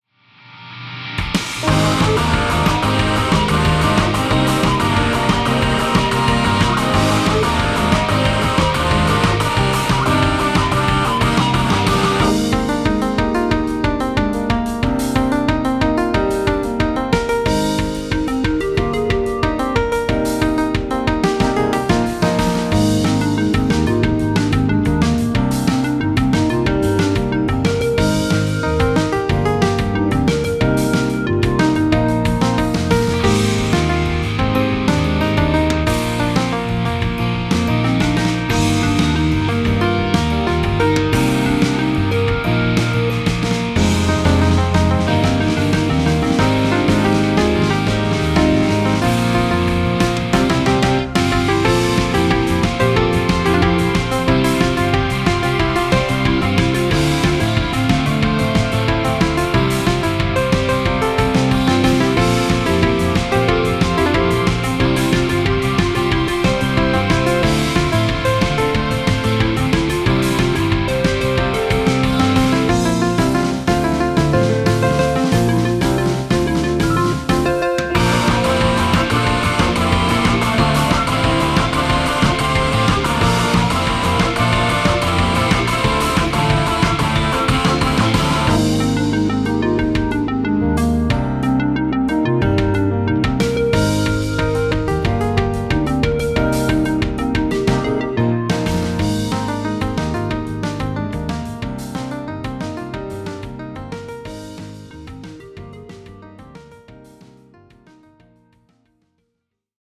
こちらはツインボーカルのロックナンバーとなっております。